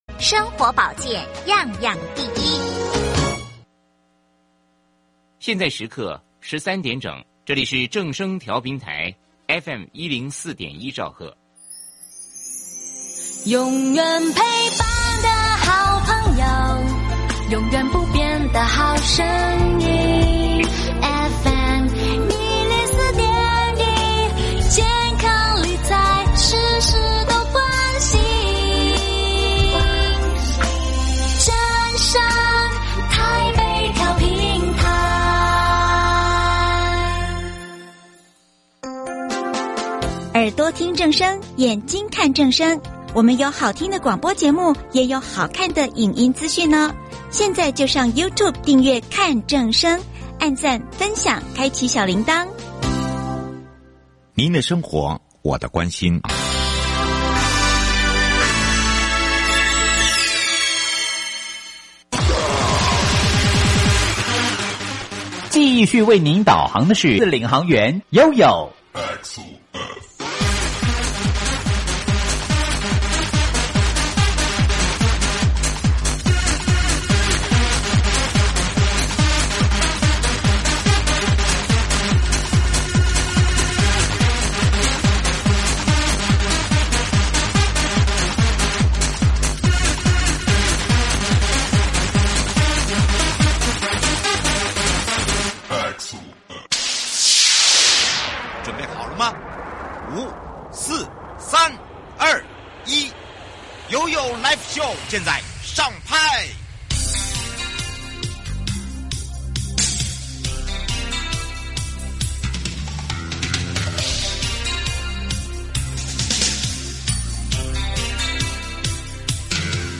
受訪者： 營建你我他 快樂平安行~七嘴八舌講清楚~樂活街道自在同行! 市區道路共識會議(第二集) 行無礙協會以推動無障礙生活為目標，積極鼓勵身心障礙者發聲，並參與相關之政府組織委員會議反應需求。是否有看過哪些優良地區值得政府借鏡參考?